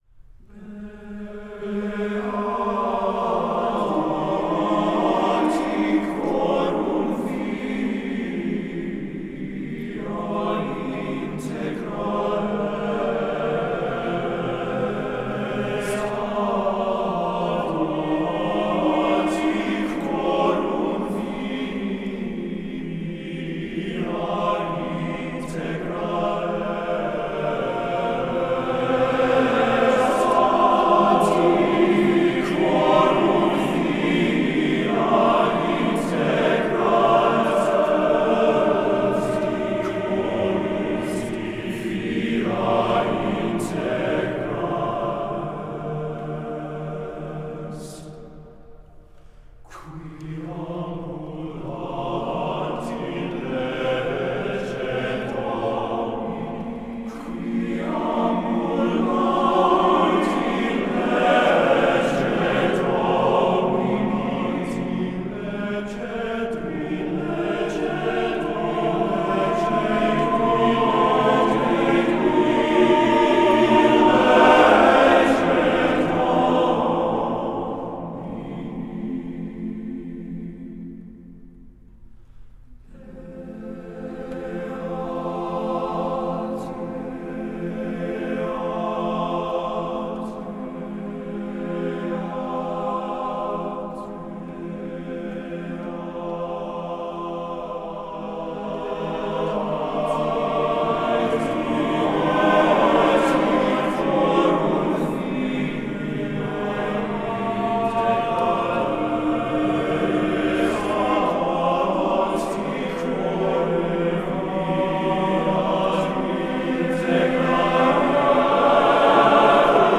Voicing: TBB